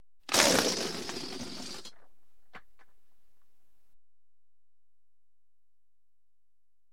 Звук, где фугу сдувается (имитация)